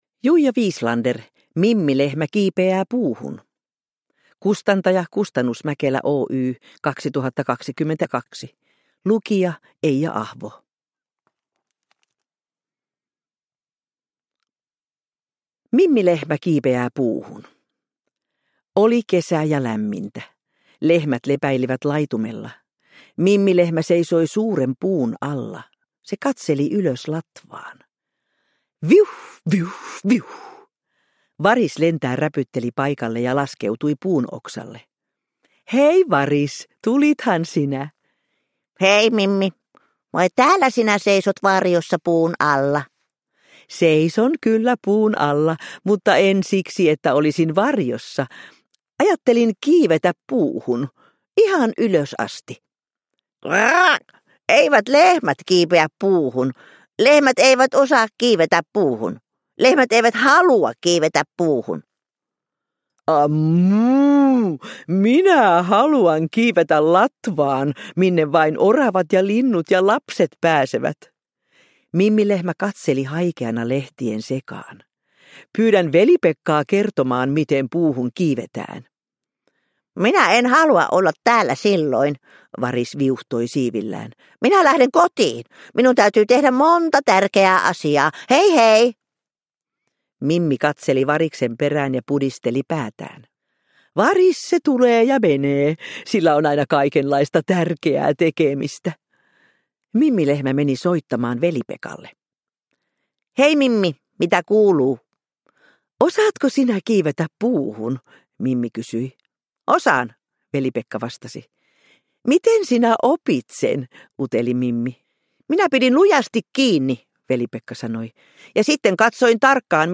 Mimmi Lehmä kiipeää puuhun – Ljudbok – Laddas ner